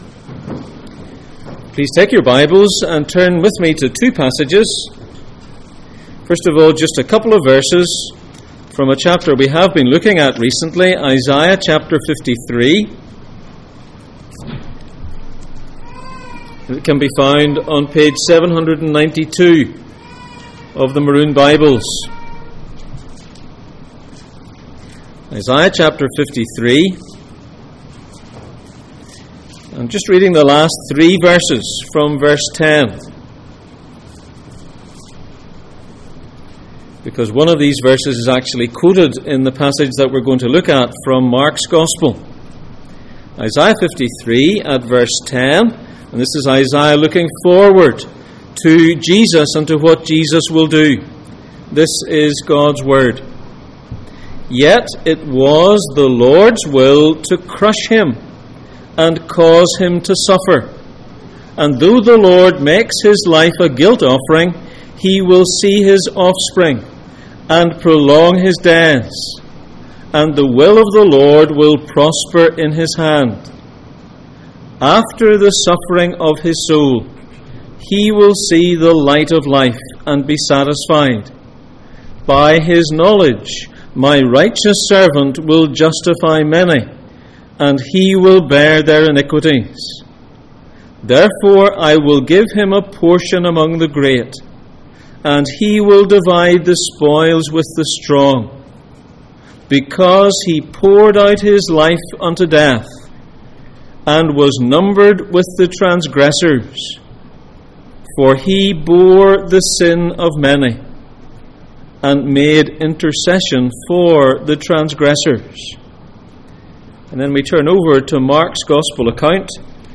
Jesus in Mark Passage: Mark 15:21-32, Isaiah 53:10-12 Service Type: Sunday Morning %todo_render% « Why was Jesus crucified?